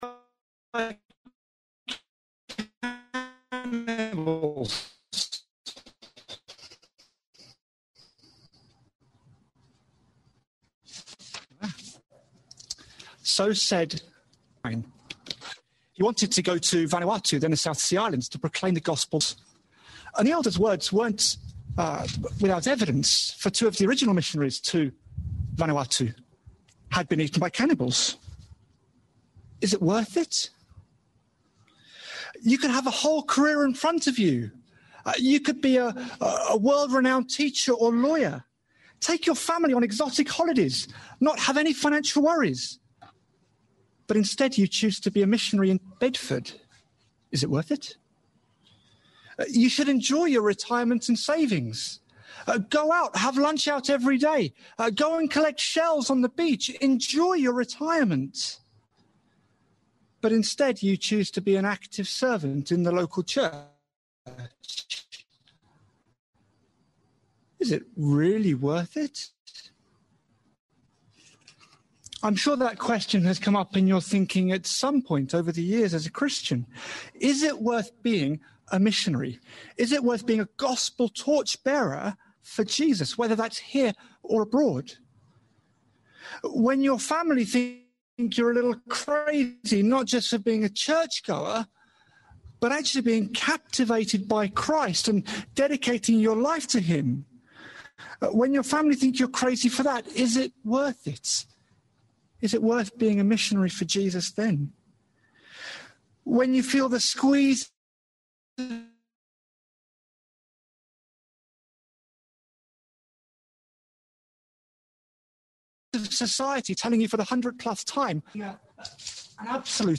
Single Sermon | Hope Church Goldington
We do apologise for the audio quality of the recording.